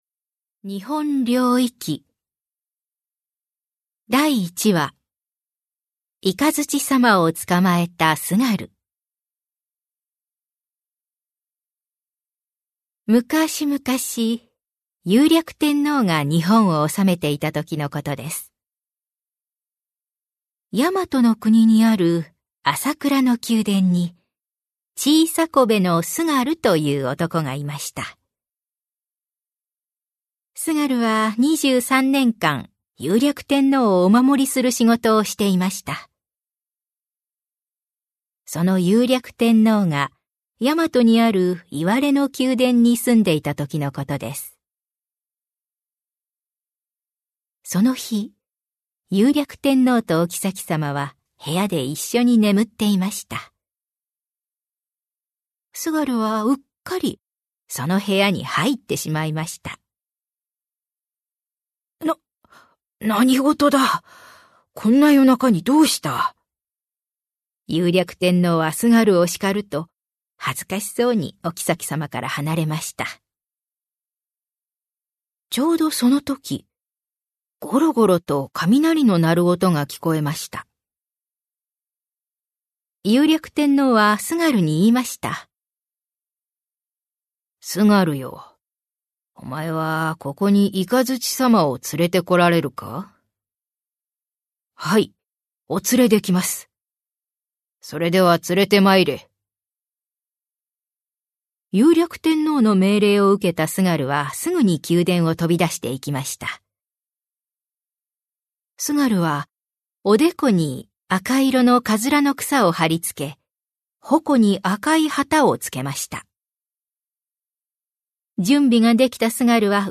[オーディオブック] 日本霊異記
奈良時代の仏教成立期から千年以上も語り継がれ仏教文学の源流といわれる最古の仏教説話集全116話のわかりやすくたのしい朗読を収録